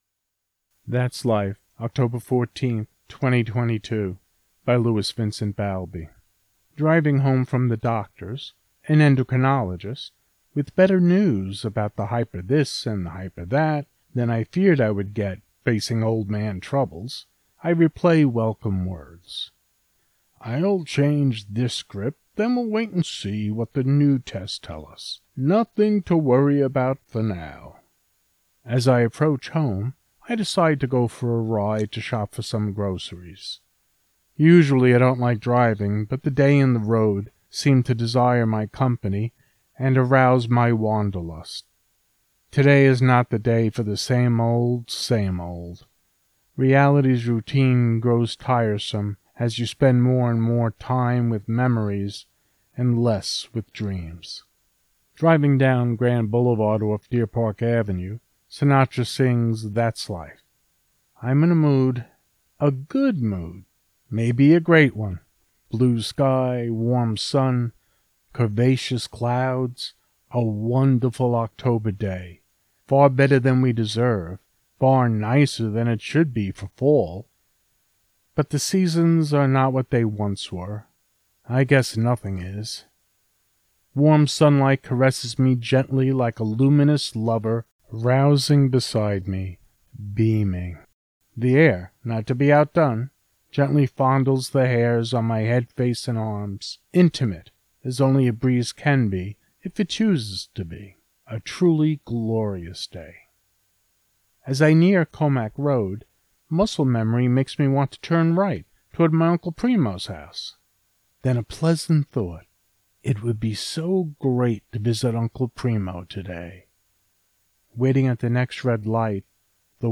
Thats Life Poem